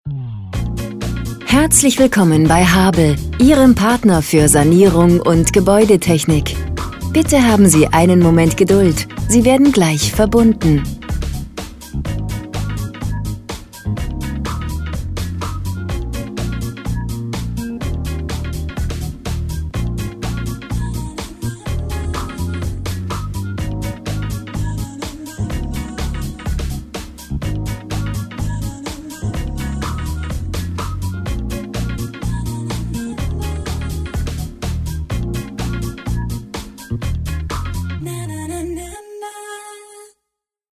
Telefonansage Gebäudetechnik
IVR Ansage: